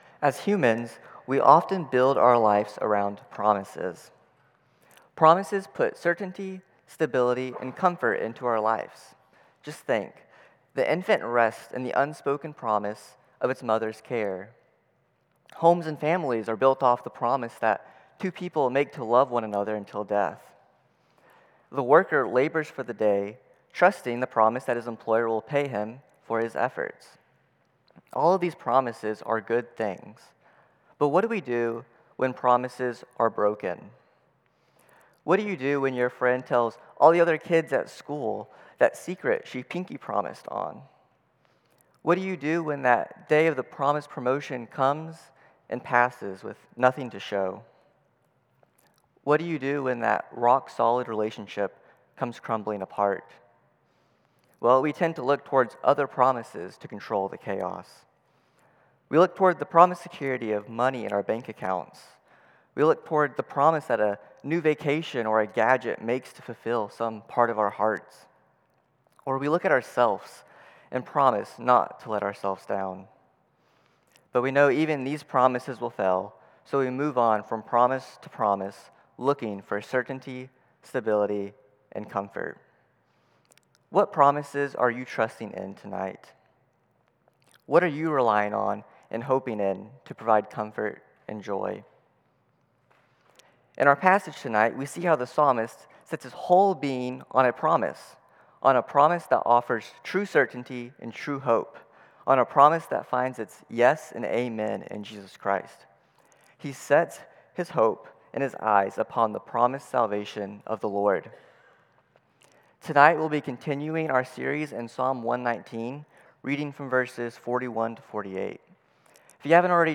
CCBC Sermons Psalm 119:41-48 Jul 06 2025 | 00:22:05 1x 00:00 / 00:22:05 Subscribe Share Apple Podcasts Spotify Overcast RSS Feed Share Link Embed